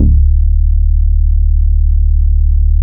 CV BASS 2.wav